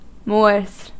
Ääntäminen
Ääntäminen US Haettu sana löytyi näillä lähdekielillä: englanti Käännöksiä ei löytynyt valitulle kohdekielelle. Moors on sanan moor monikko.